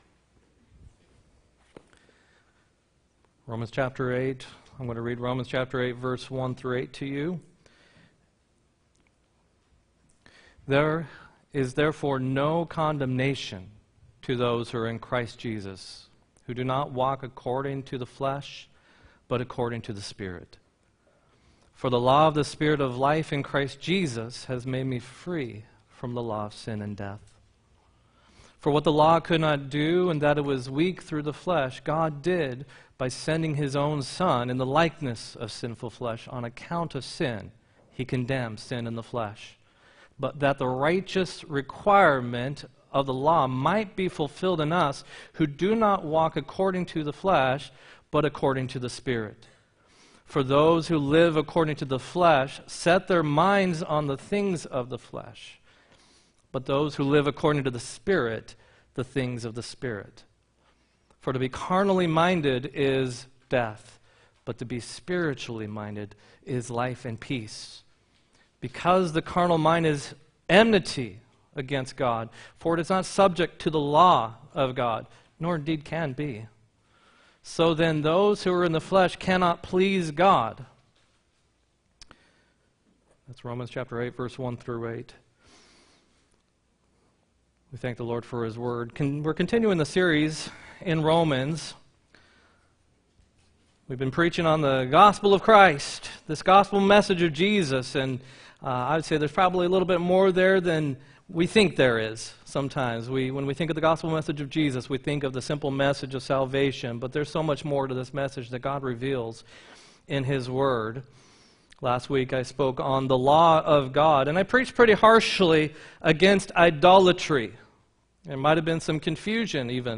8-11-18 sermon